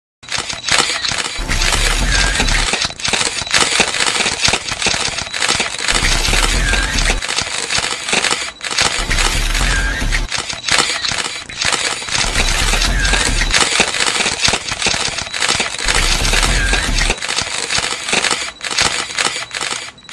meme